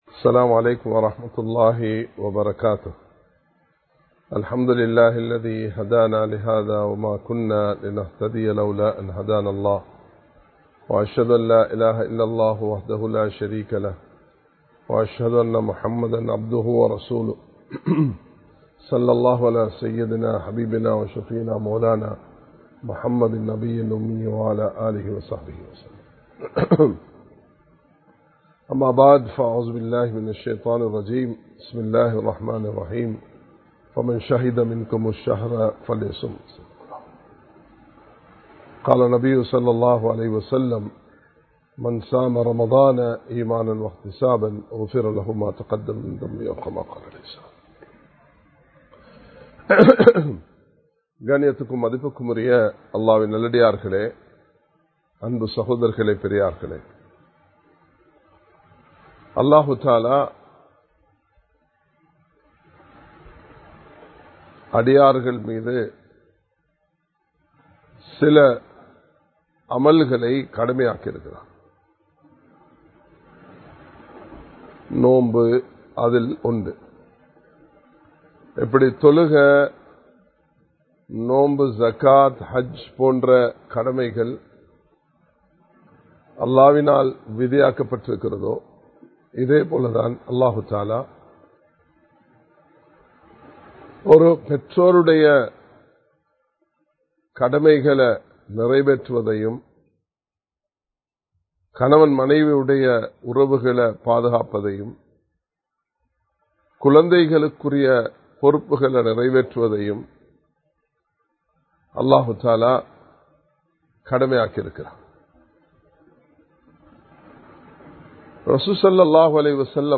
ரமழான் சொல்லும் பாடம் | Audio Bayans | All Ceylon Muslim Youth Community | Addalaichenai
Live Stream